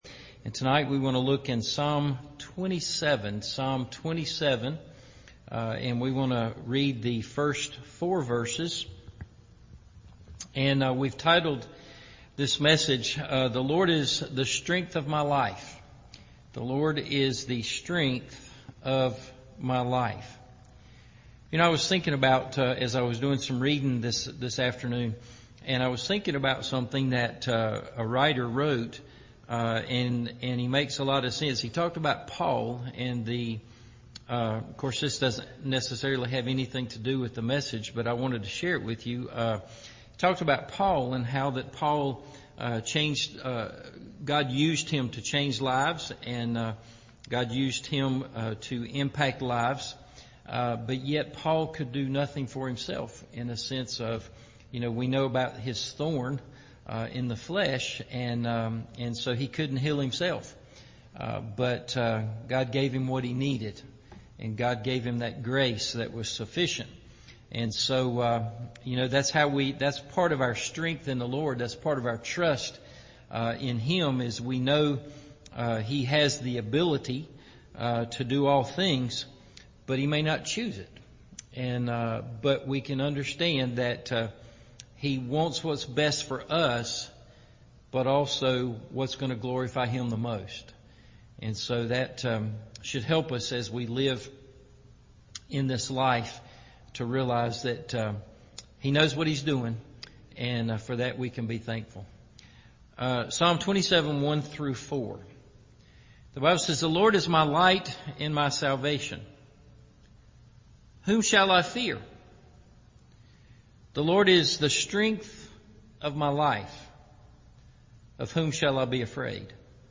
The Lord Is The Strength Of My Life – Evening Service